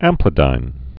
(ămplĭ-dīn)